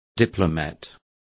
Complete with pronunciation of the translation of diplomat.